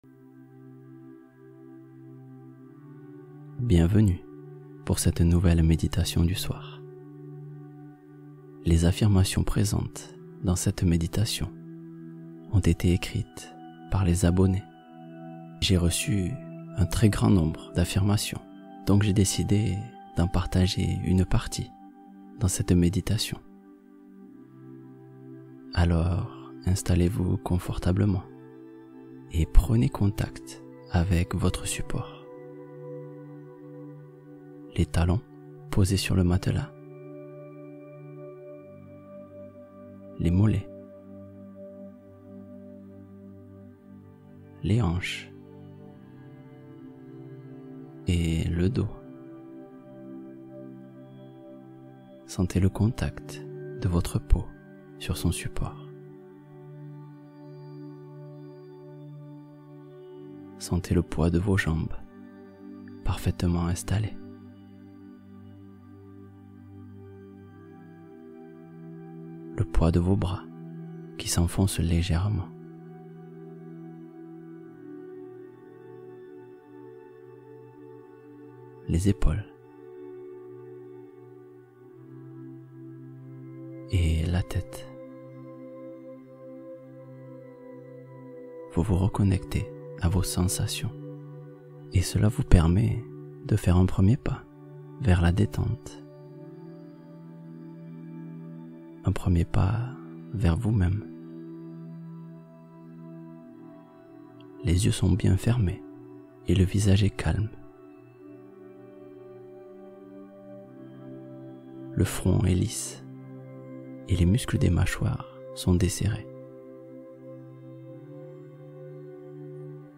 La chaleur de l’hiver : méditation du soir avec affirmations positives